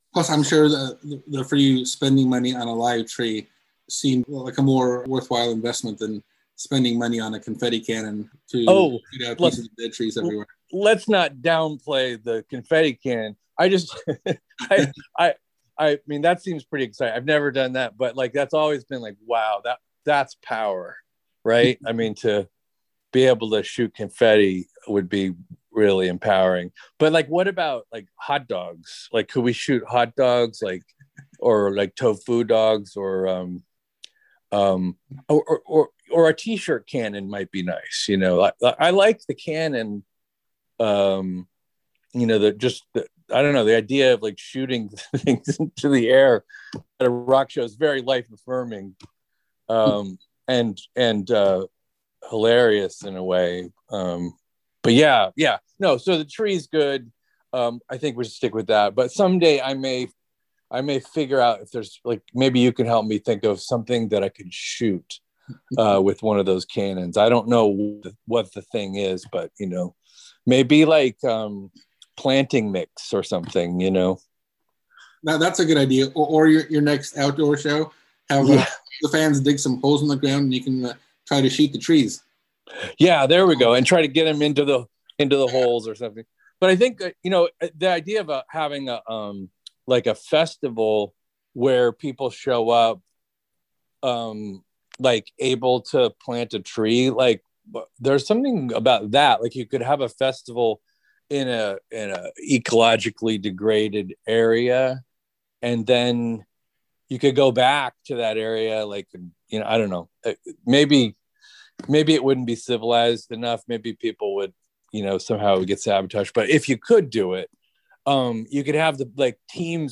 John McCrea on reforestation effort, 1st CAKE LP in a decade | Interview
SOUNDBITE